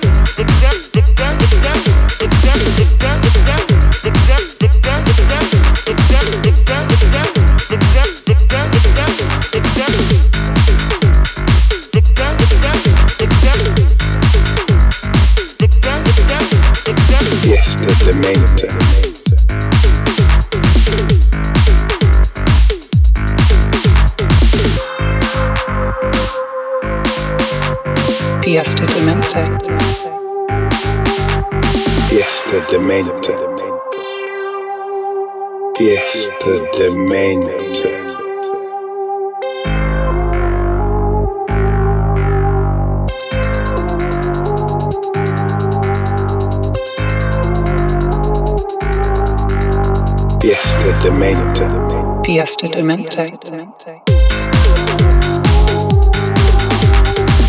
a mix of house, techno and electro rhythms.